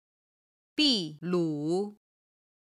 秘鲁　(Bì lǔ)　ペルー